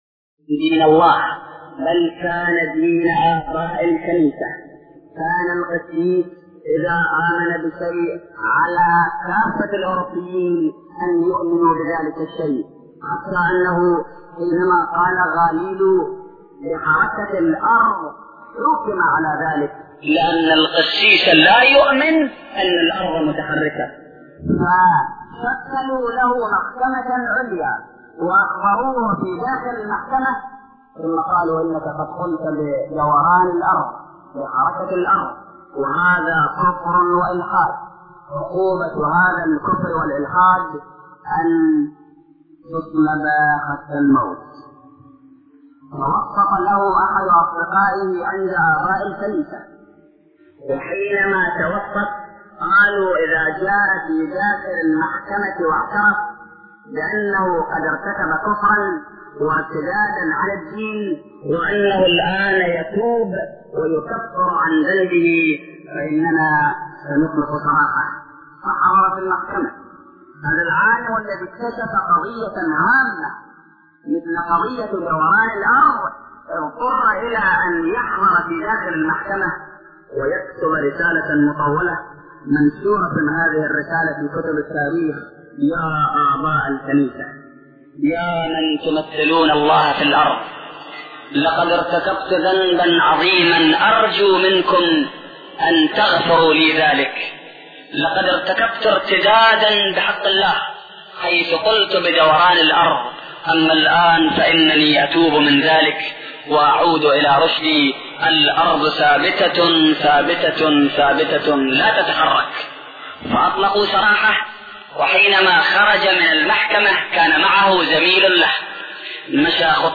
محاضرات متفرقة